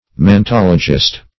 Mantologist \Man*tol"o*gist\, n. One who is skilled in mantology; a diviner.